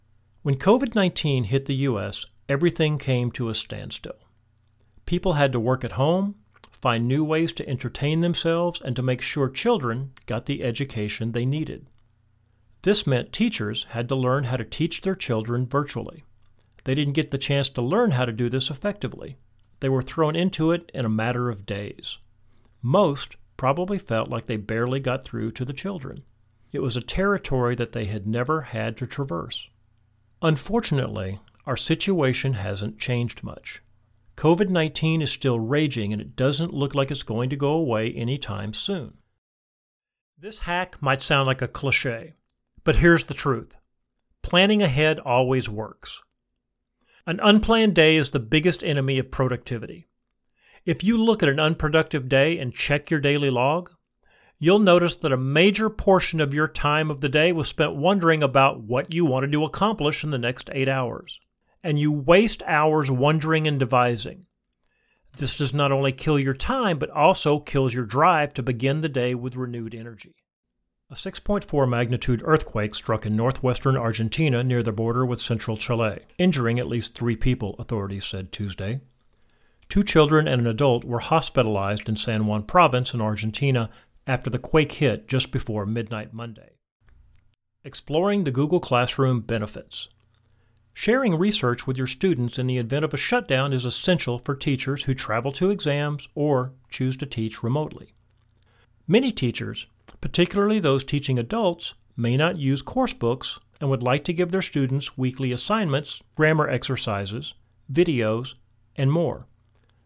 Originally from Texas, I have a faint accent that can be amped up when needed.
Audiobook-Demo.mp3